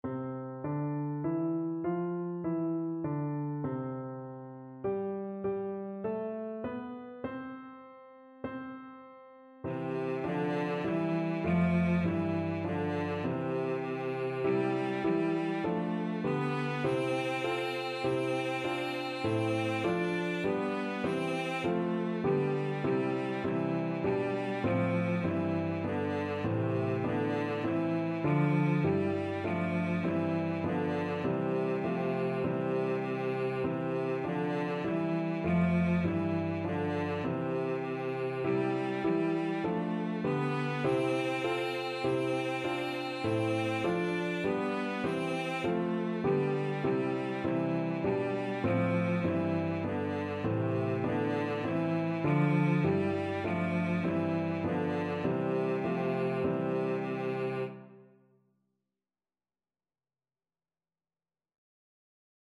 Traditional Trad. Unto us is Born a Son Cello version
Christmas Christmas Cello Sheet Music Unto us is Born a Son
Cello
4/4 (View more 4/4 Music)
C major (Sounding Pitch) (View more C major Music for Cello )
Traditional (View more Traditional Cello Music)